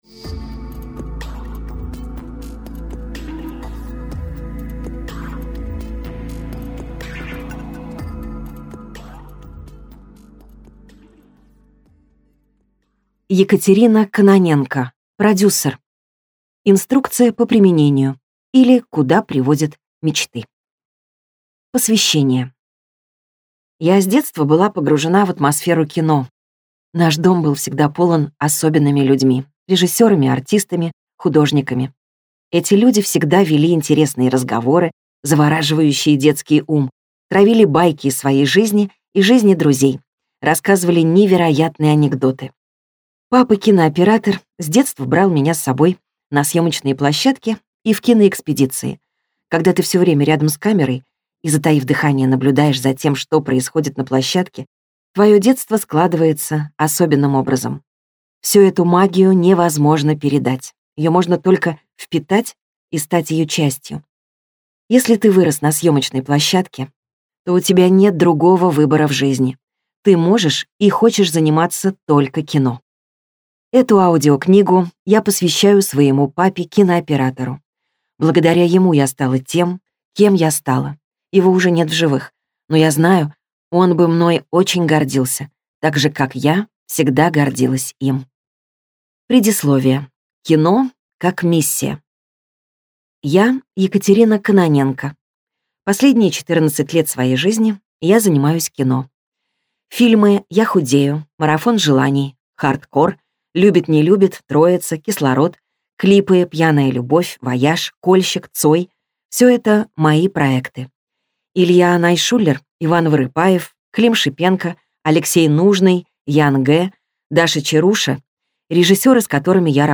Аудиокнига Продюсер. Инструкция по применению, или Куда приводят мечты | Библиотека аудиокниг